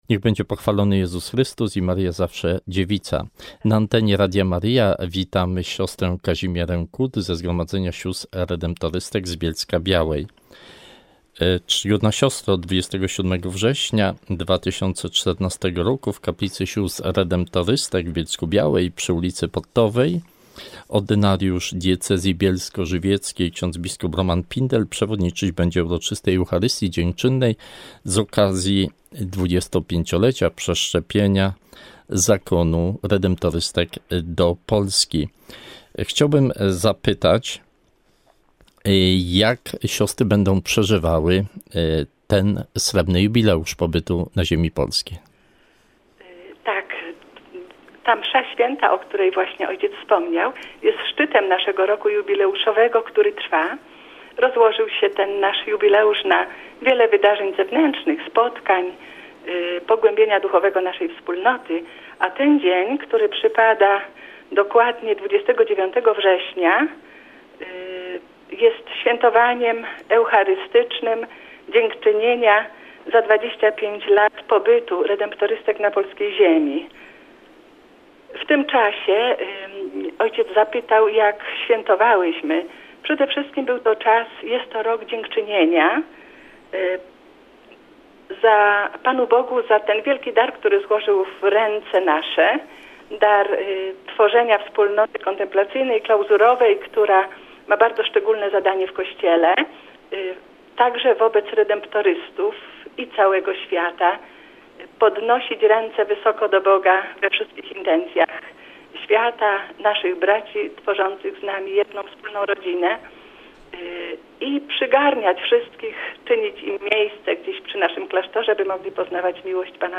wywiadzie dla słuchaczy Radia Maryja.